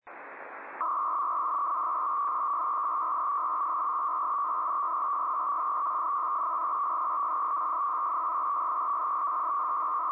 chip64.mp3